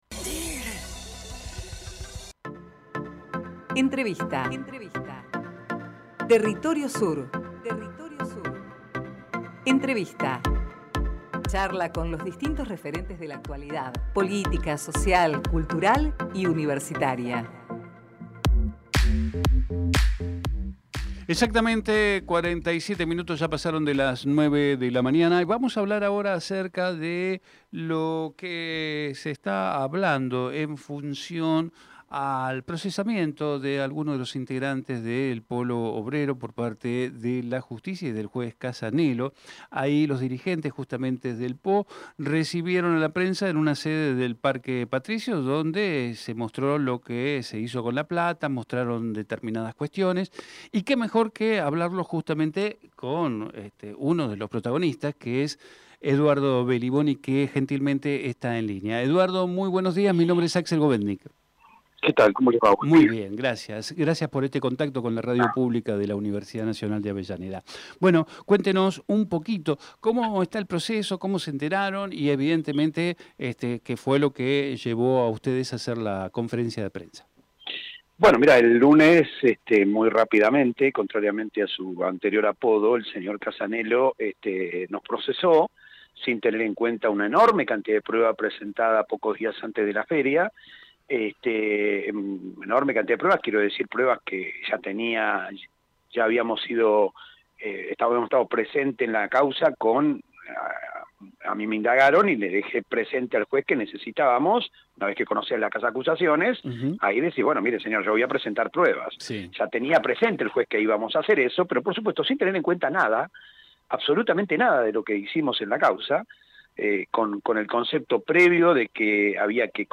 TERRITORIO SUR - EDUARDO BELLIBONI Texto de la nota: Compartimos la entrevista realizada en "Territorio Sur" a Eduardo Belliboni, dirigente del Polo Obrero. Archivo de audio: TERRITORIO SUR - EDUARDO BELLIBONI Programa: Territorio Sur